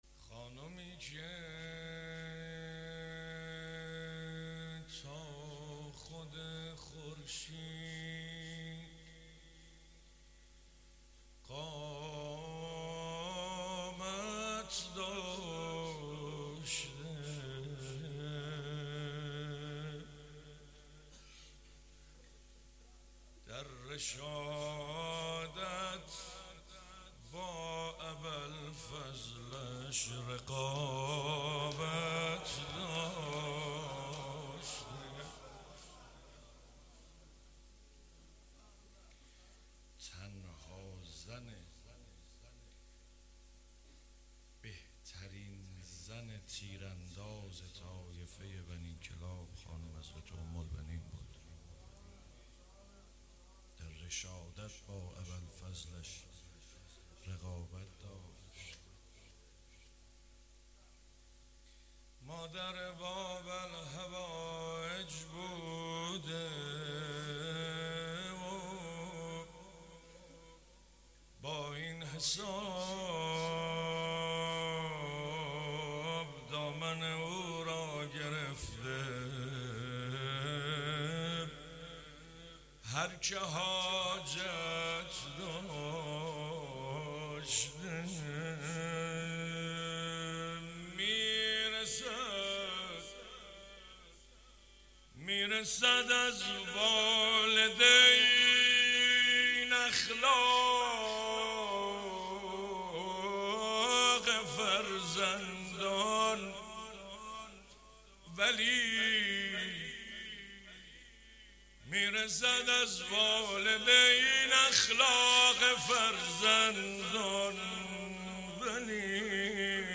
8 اسفند 96 - اهواز - روضه - خانومی که تا خود خورشید